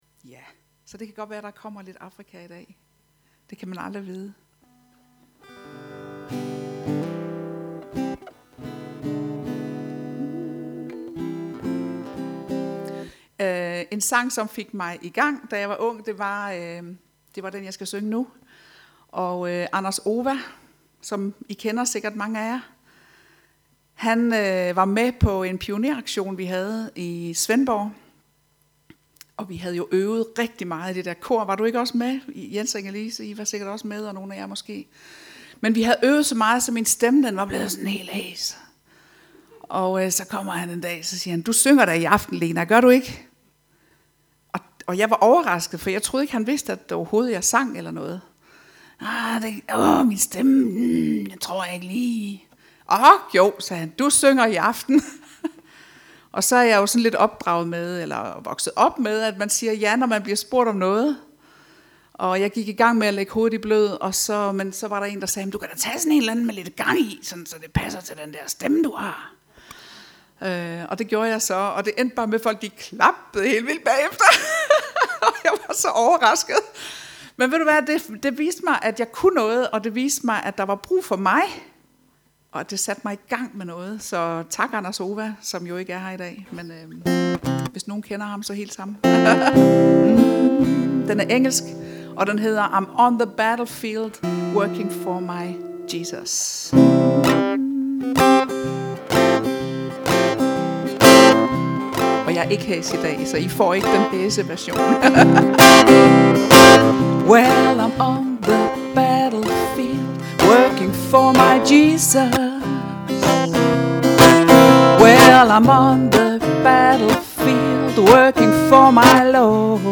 Festivalgudstjeneste